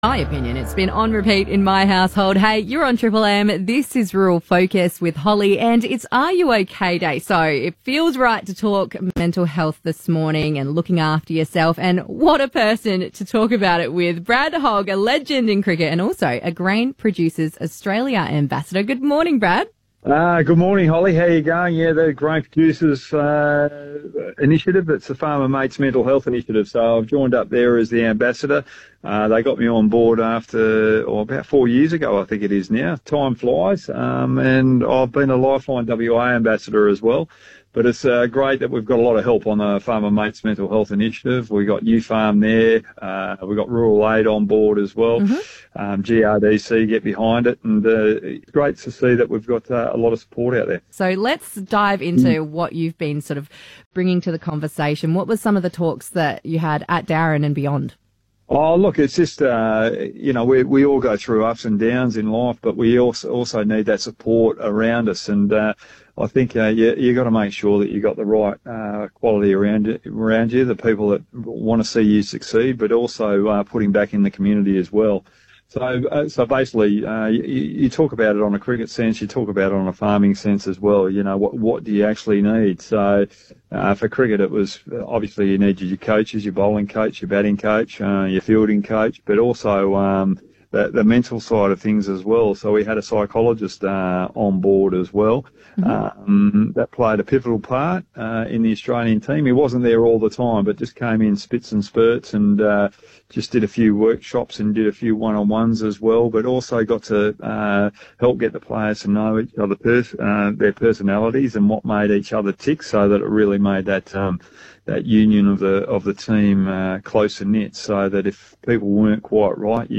Farmer Mates Mental Health Initiative National Ambassador and Aussie cricketing favourite Brad Hogg speaks to Rural Focus' about the importance of mental health and wellbeing for RU OK Day and every day of the year.